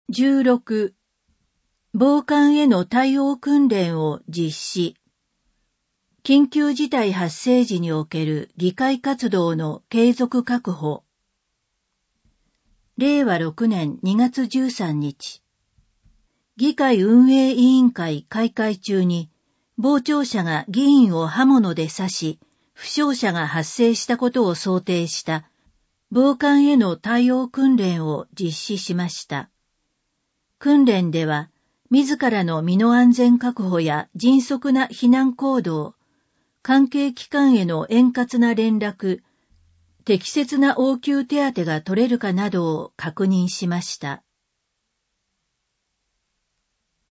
「Windows Media Player」が立ち上がり、埼玉県議会だより 177号の内容を音声（デイジー版）でご案内します。